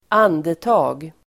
Uttal: [²'an:deta:g]